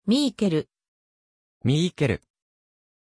Aussprache von Miquel
pronunciation-miquel-ja.mp3